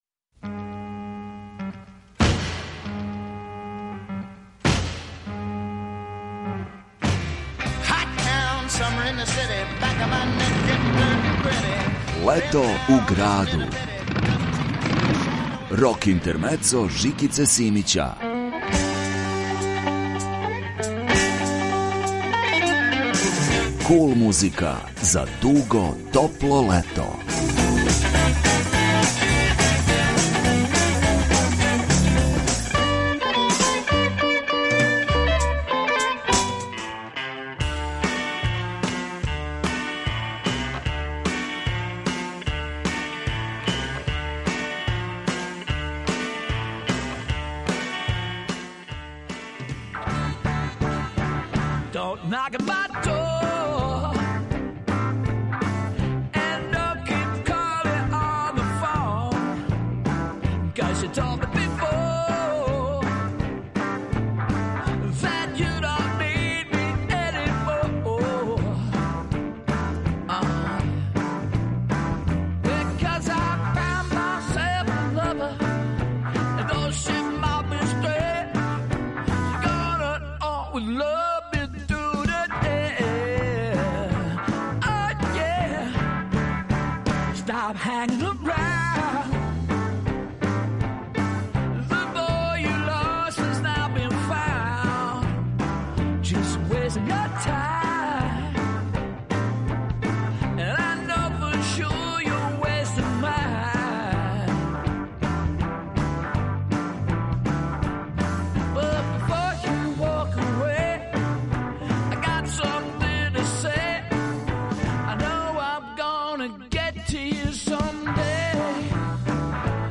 Kул музика за дуго топло лето.